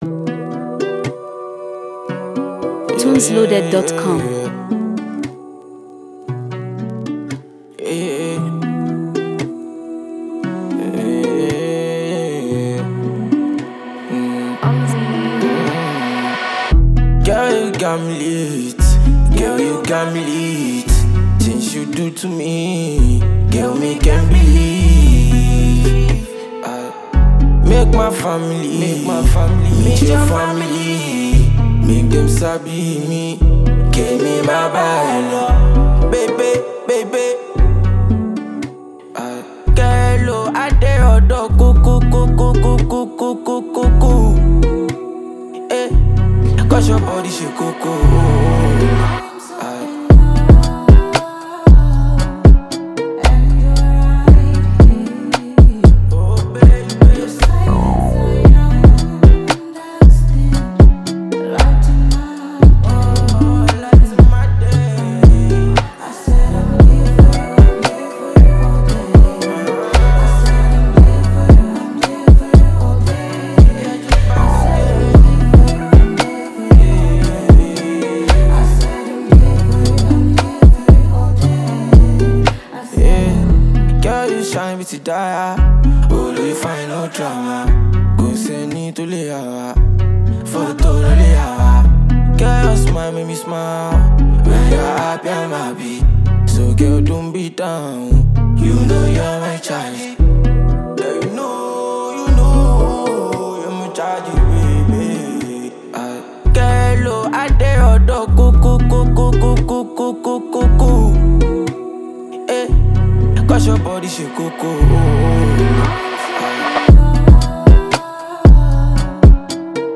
Blending smooth vocals with dynamic instrumentals